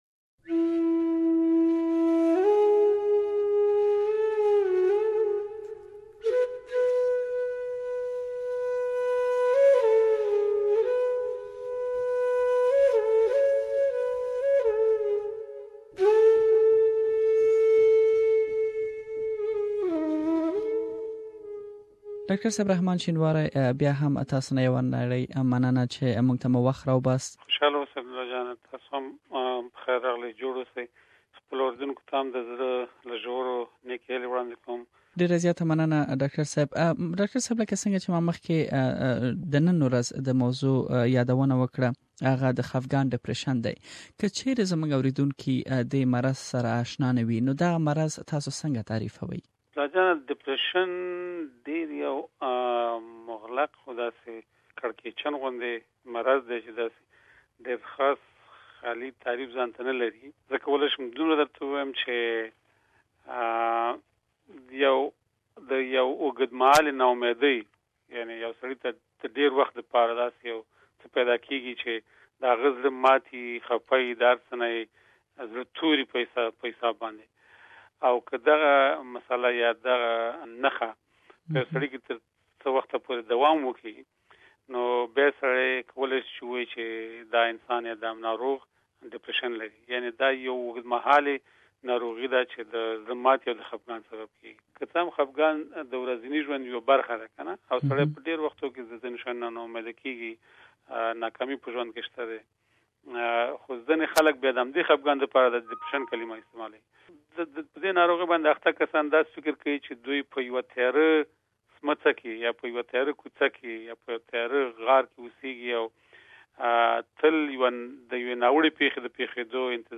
Please listen to the first part of the interview here Share